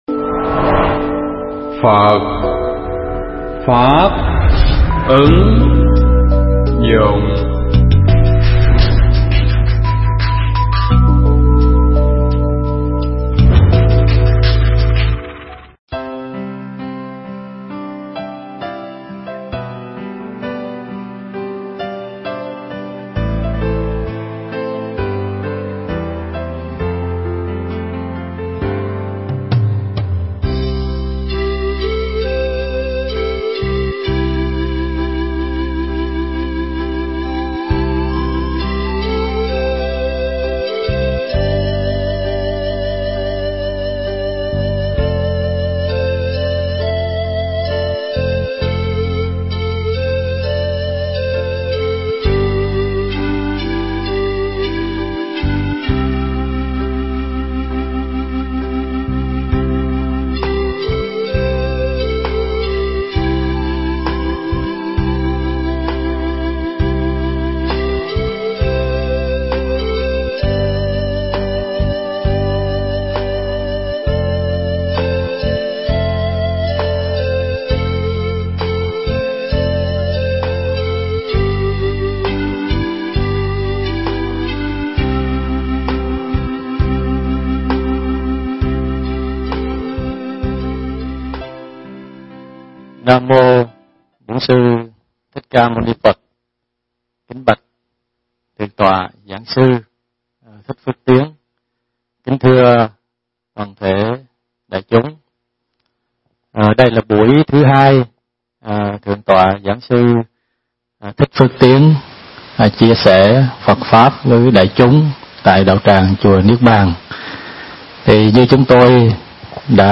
Mp3 Pháp thoại Chán Sự Đời
tại chùa Niết Bàn (Hoa Kỳ)